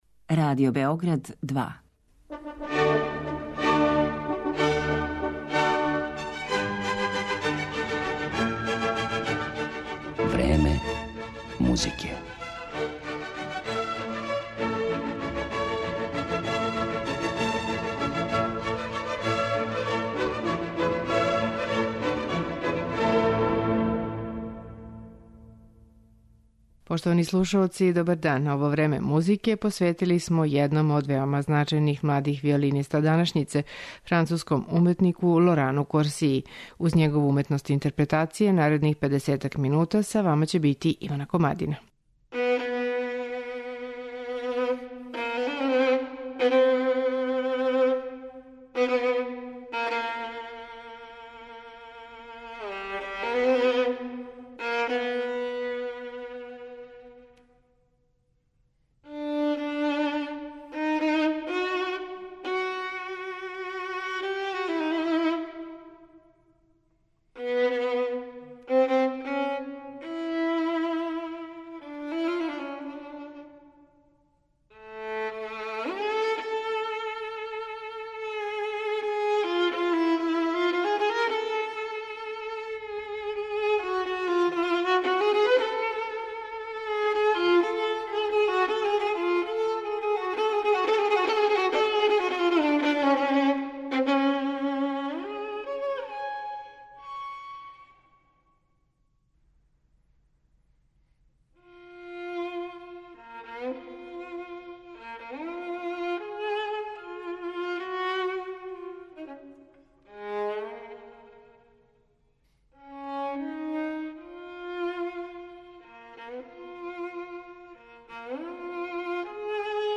млади француски виолиниста